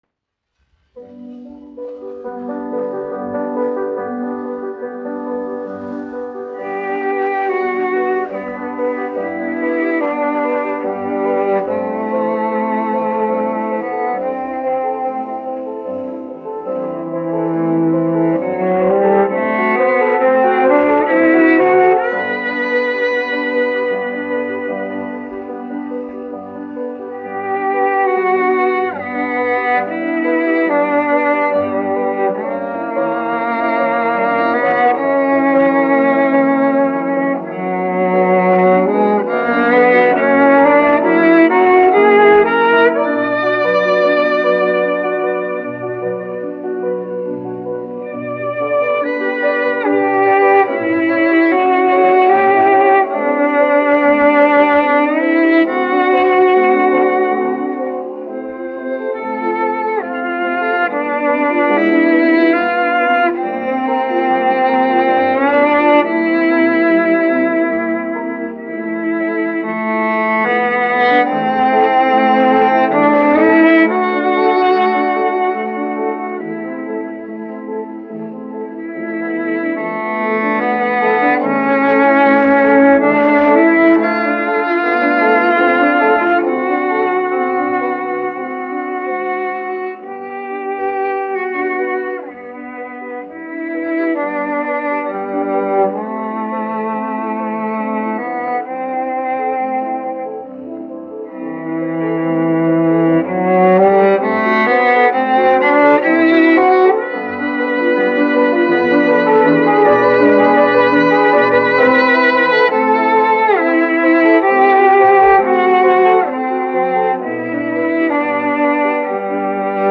1 skpl. : analogs, 78 apgr/min, mono ; 25 cm
Čella un klavieru mūzika
Skaņuplate